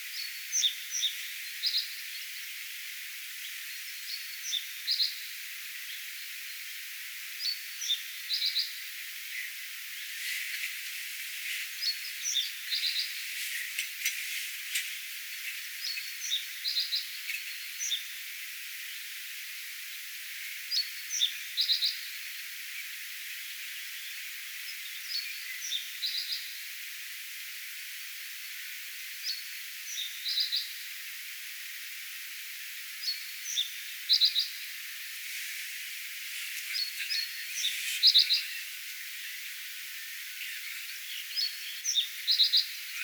pajusirkun laulua